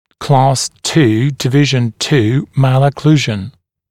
[klɑːs tuː dɪ’vɪʒn tuː ˌmælə’kluːʒn][кла:с ту: ди’вижн ту: ˌмэлэ’клу:жн]II класс, 2 подкласс аномалии прикуса